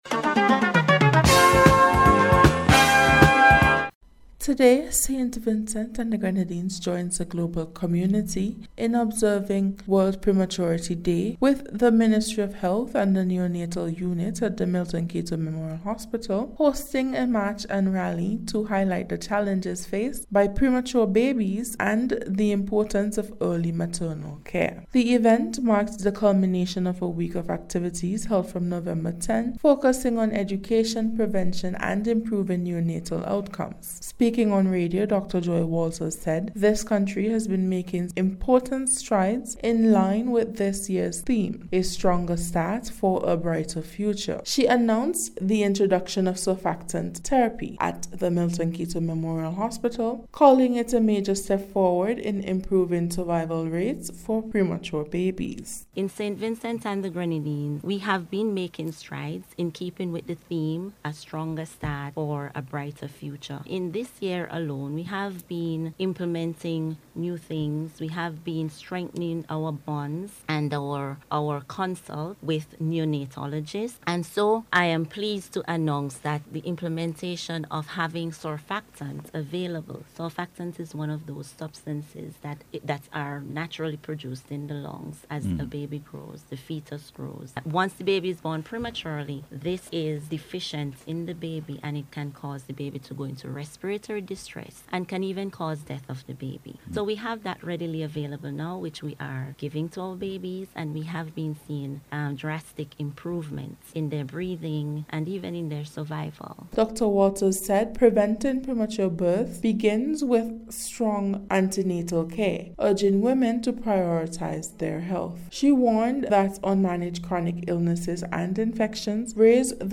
In today’s special report, we focus on World Prematurity Day and the latest breakthroughs in caring for premature babies in St. Vincent and the Grenadines.